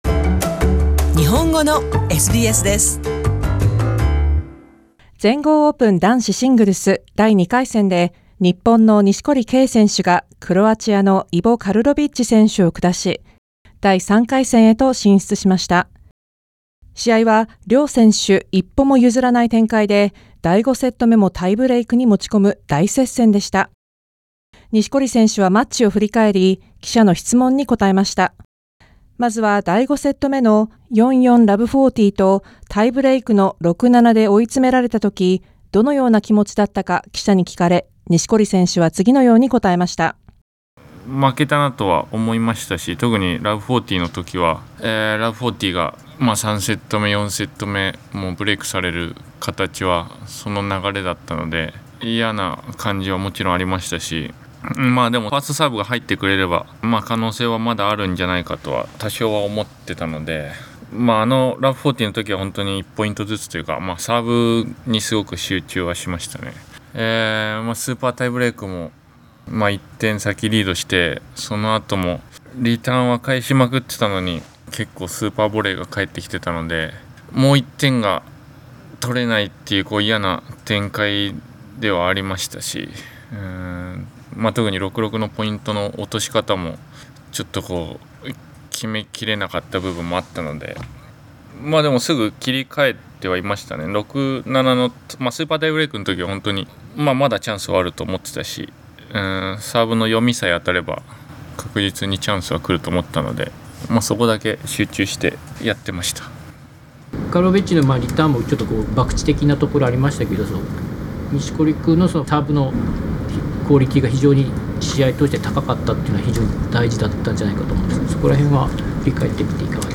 全豪テニス 錦織 カルロビッチとの大接戦後の記者会見
錦織選手はマッチを振り返り、記者の質問に答えました。